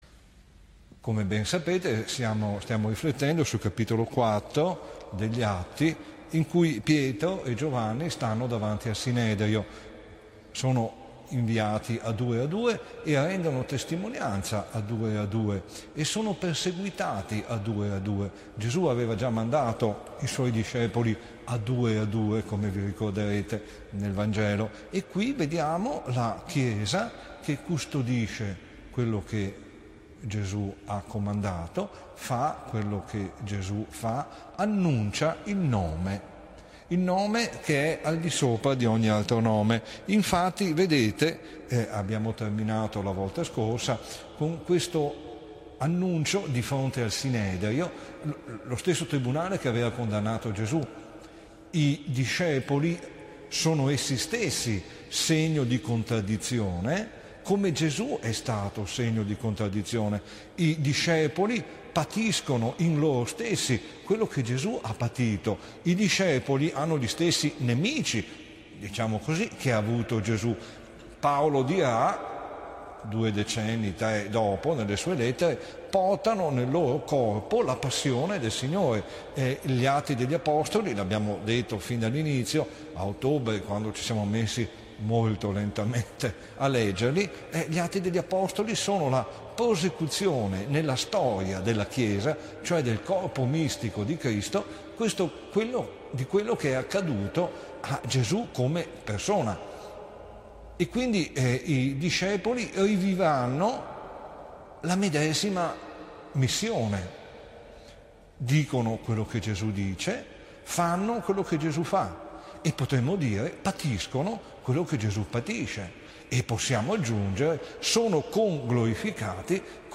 Lectio Divina, At 4,13-22.mp3